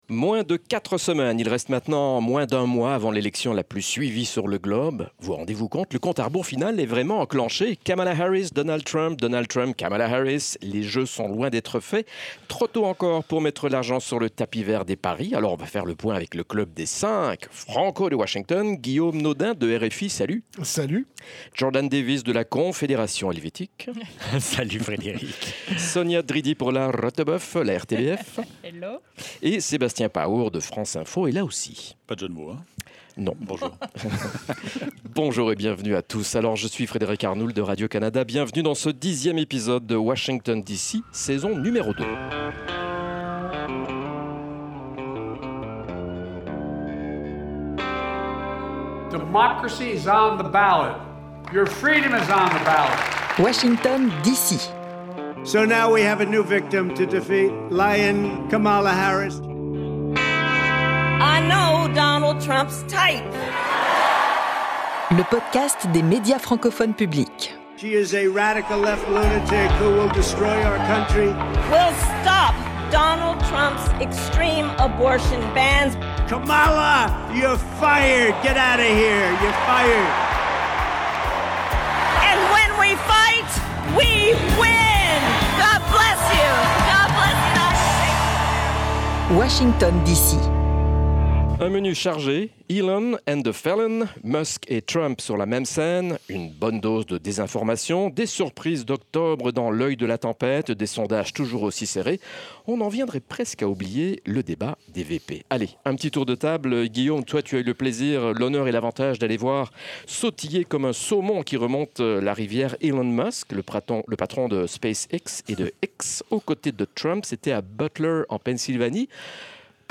Dans ce nouvel épisode nous nous intéressons aux dernières semaines avant l'élection présidentielle américaine. Une période marquée par des événements tumultueux et une campagne électorale serrée. L'analyse des correspondants des Médias francophones publics (MFP) dans le 10e épisode de cette saison 2 de Washington d'ici.